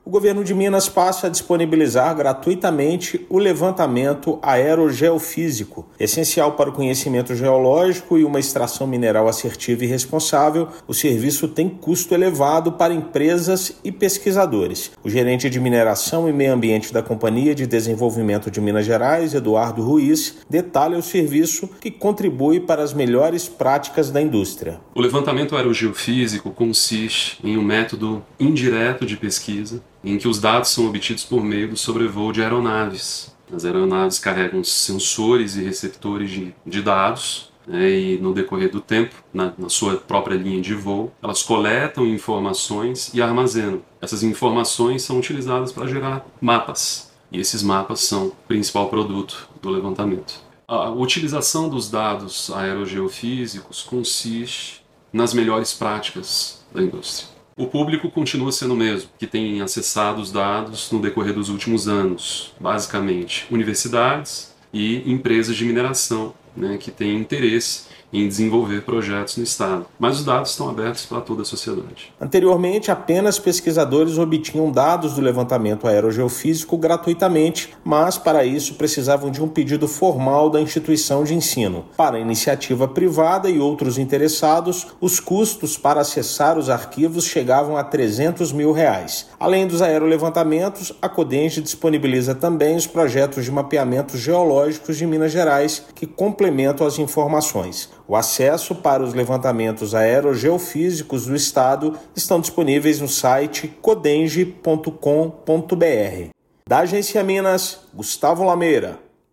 Serviço, que antes era pago, agora será oferecido pela Codemge sem custos e pode contribuir para impulsionar uma mineração mais precisa e responsável em Minas. Ouça matéria de rádio.